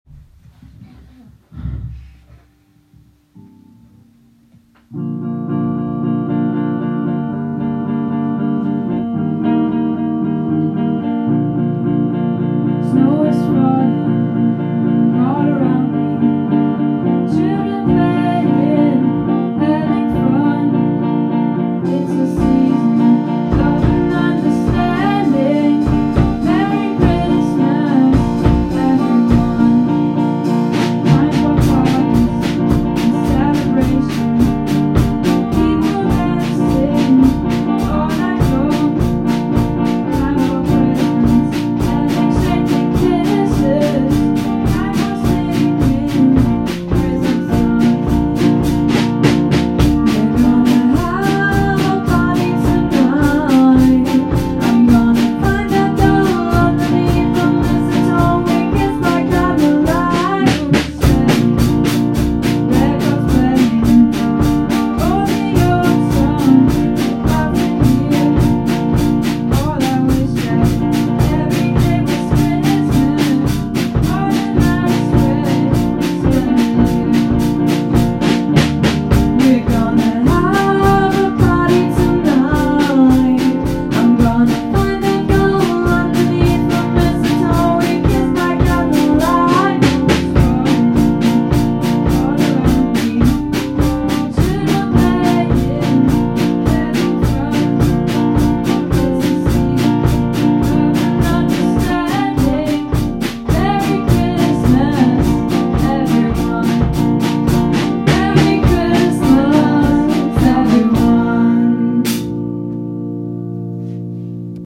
von unserer MCG-Band THE MATES
13.12-Weihnachtsliche-Gruesse-von-unserer-MCG-Band-THE-MATES.m4a